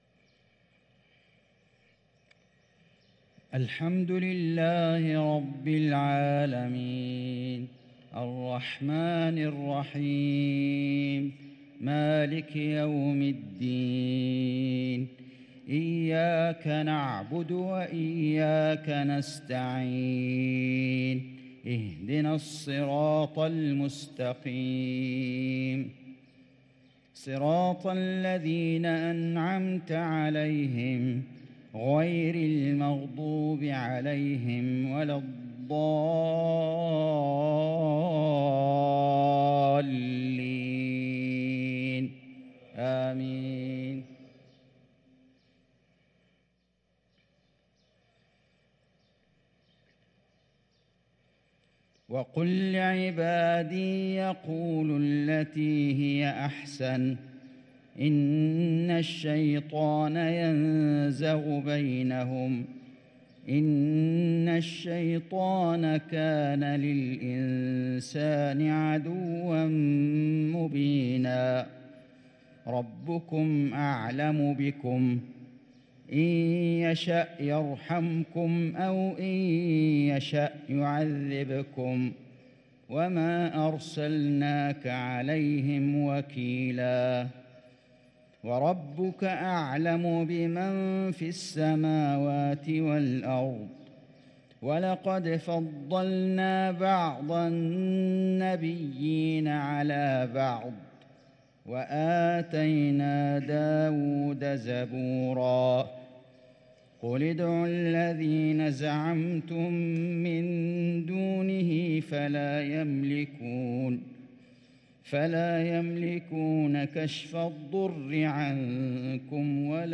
صلاة المغرب للقارئ فيصل غزاوي 29 ربيع الأول 1444 هـ
تِلَاوَات الْحَرَمَيْن .